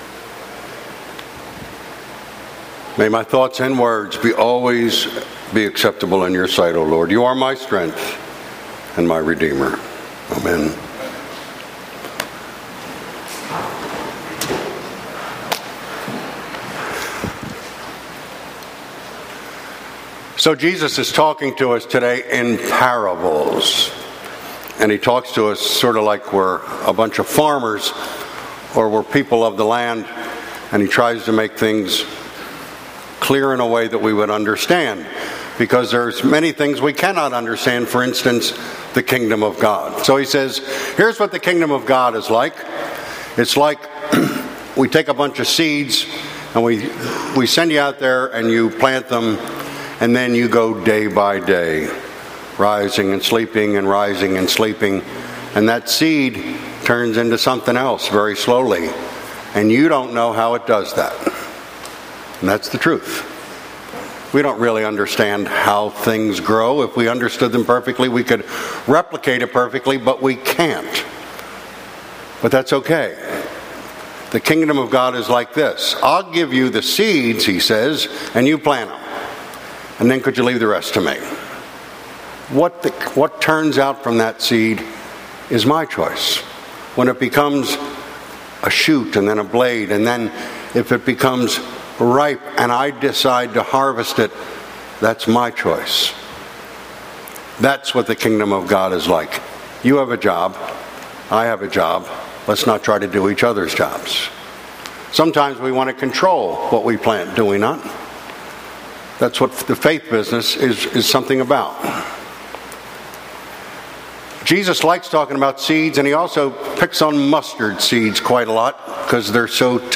Saint Peter's Episcopal Church :: Phoenixville, PA
Sermon